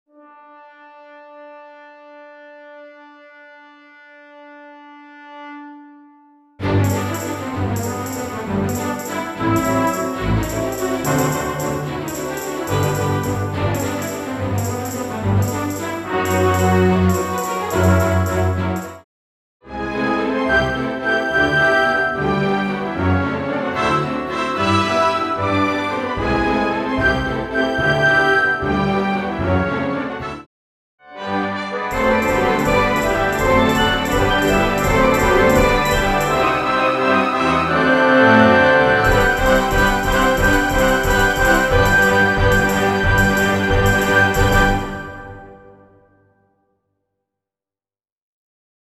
Full Orch accompaniment